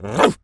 pgs/Assets/Audio/Animal_Impersonations/dog_large_bark_02.wav
dog_large_bark_02.wav